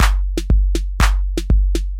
鼓环
描述：恍惚的鼓声循环
Tag: 电子 循环 技术